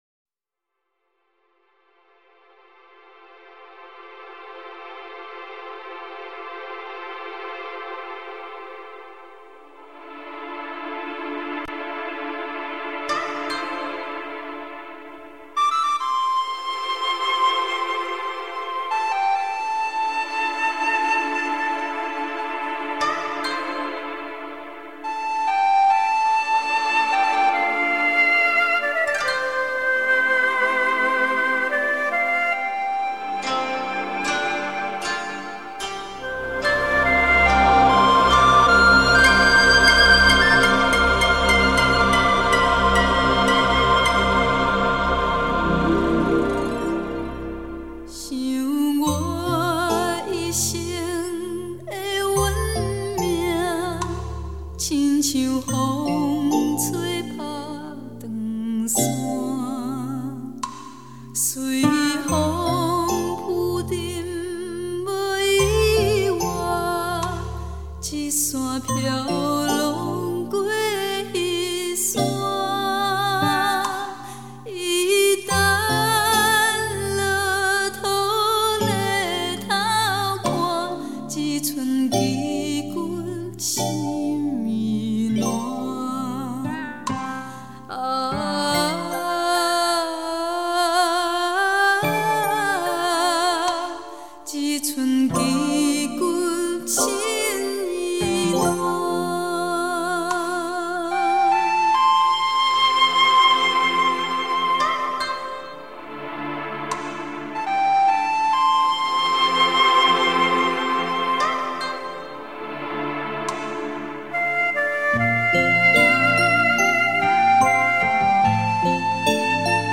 录 音 室：白金录音室
简单的乐器运用，却营造出悠悠深远的空间感